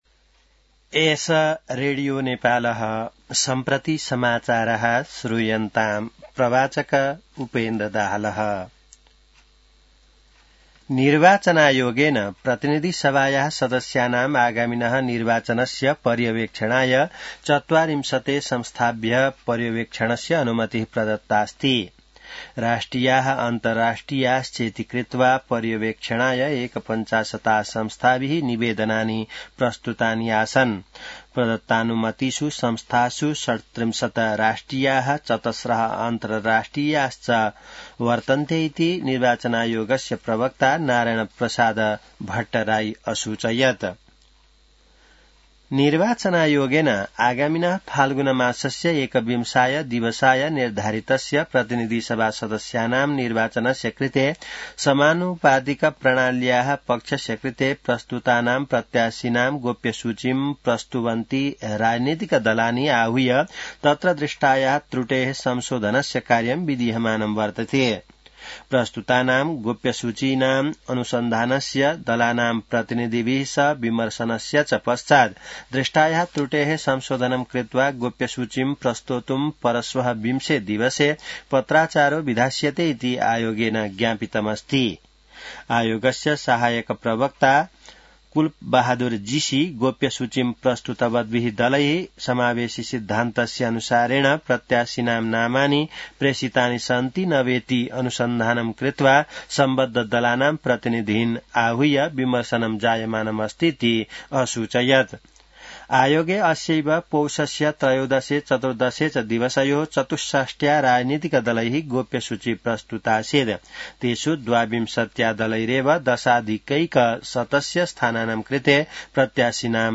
संस्कृत समाचार : १८ पुष , २०८२